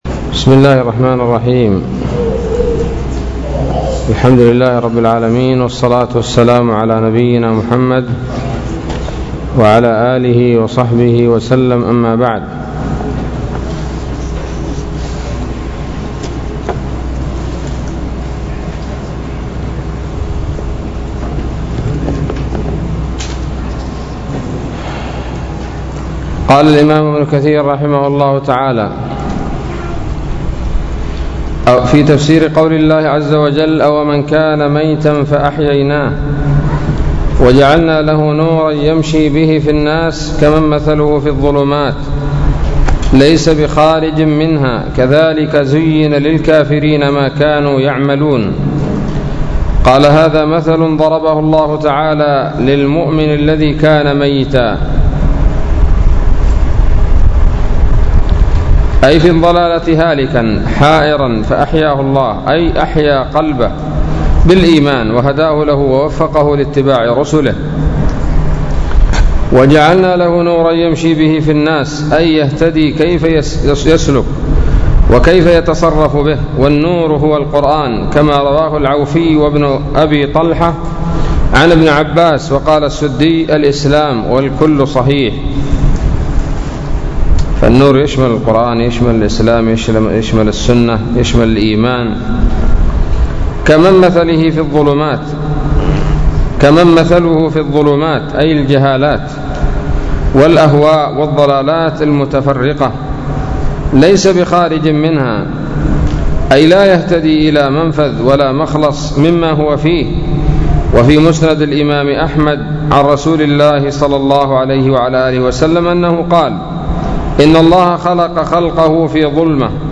الدرس الخامس والأربعون من سورة الأنعام من تفسير ابن كثير رحمه الله تعالى